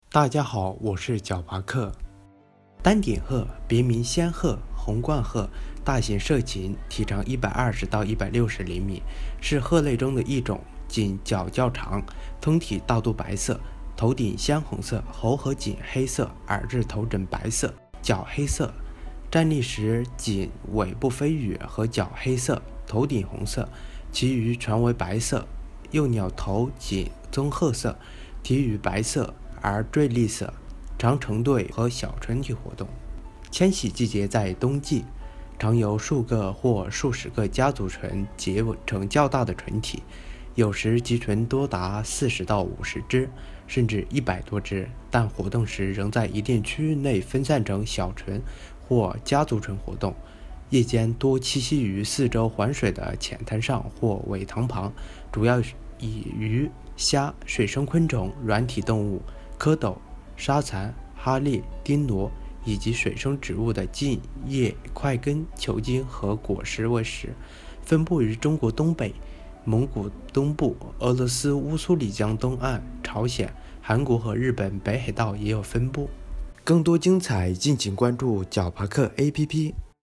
丹顶鹤----- 66666 解说词: 丹顶鹤(拉丁学名：Grus japonensis)，别名仙鹤、红冠鹤。